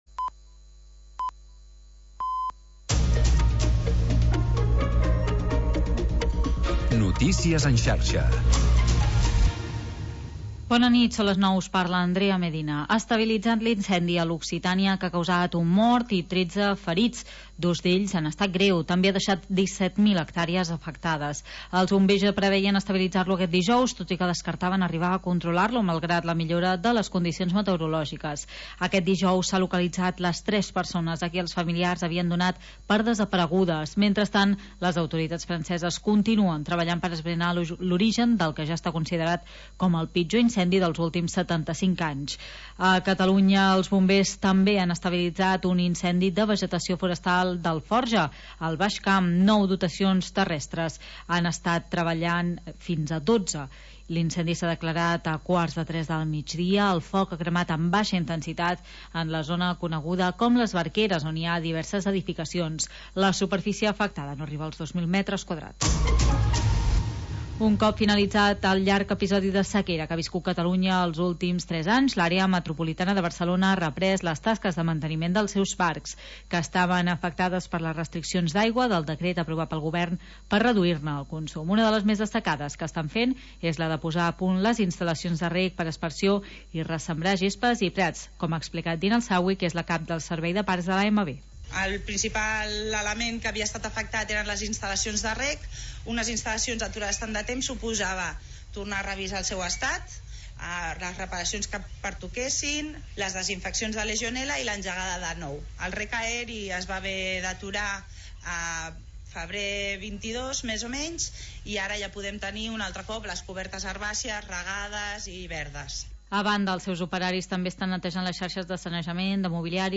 Transmissió Cantada Havaneres